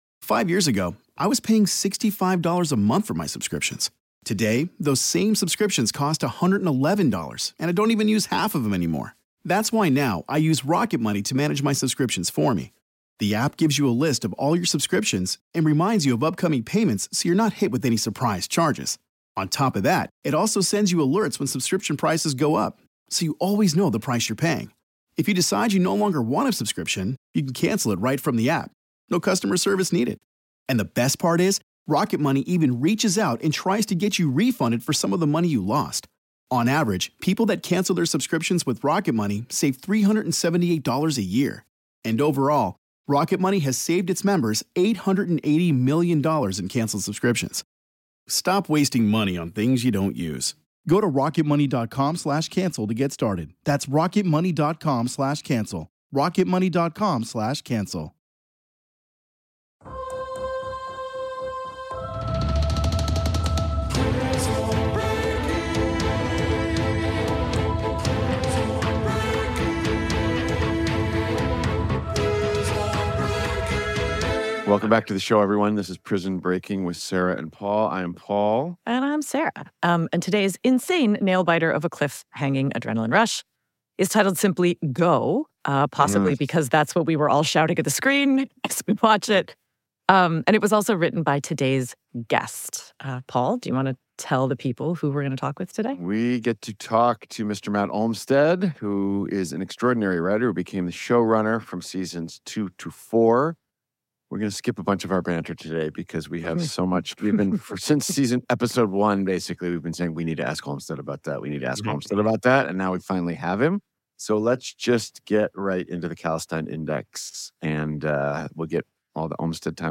With special guest "Go"'s episode writer and series Executive Producer Matt Olmstead!